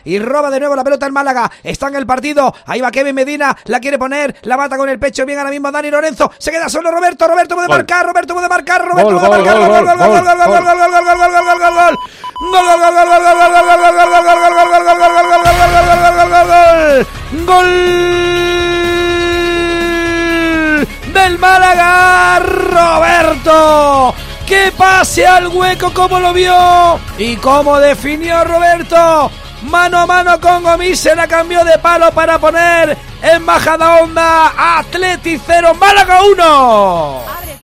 narración